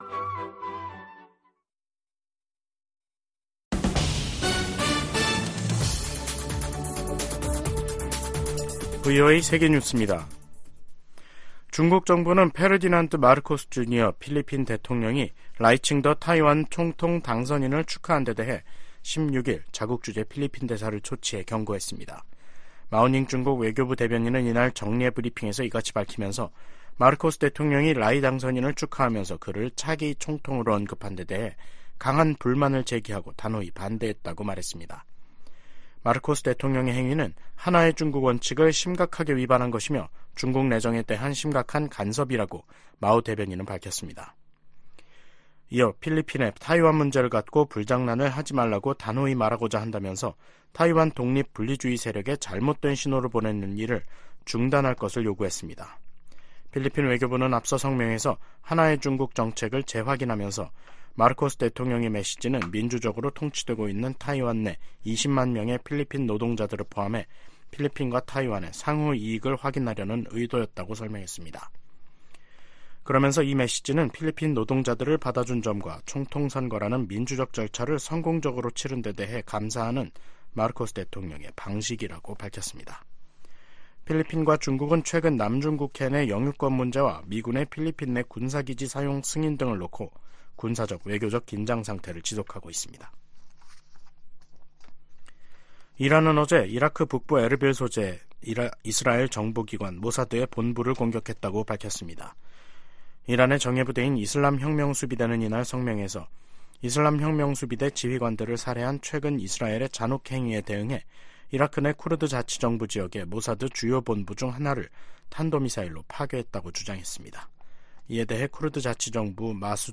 VOA 한국어 간판 뉴스 프로그램 '뉴스 투데이', 2024년 1월 16일 3부 방송입니다. 김정은 북한 국무위원장은 한국을 '제1의 적대국'으로 명기하는 헌법개정 의지를 분명히 했습니다.